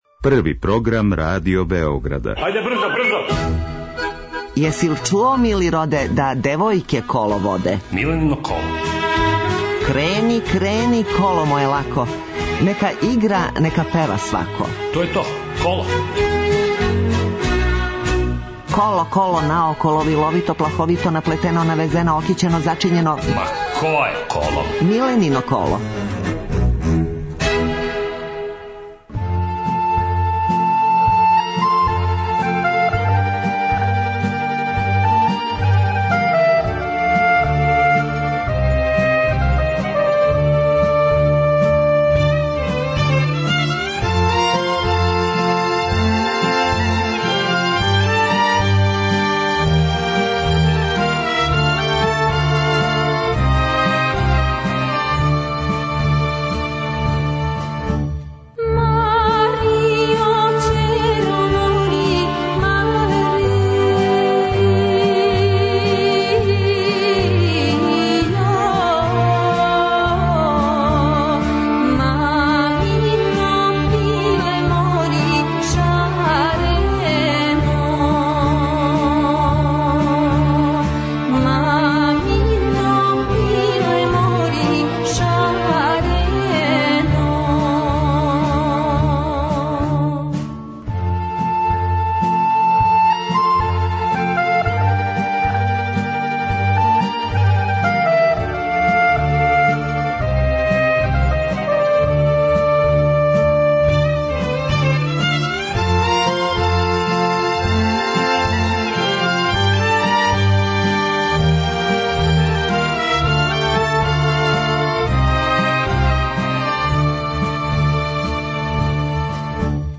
Емисија се емитује недељом од 11.05 до 12.00 о народној музици, искључиво са гостима који су на било који начин везани за народну музику, било изворну, било новокомпоновану (певачи, композитори, текстописци, музичари...). Разговор са гостом забавног карактера - анегдоте из професионалног живота, најдража песма, највећи успех, хоби и томе слично.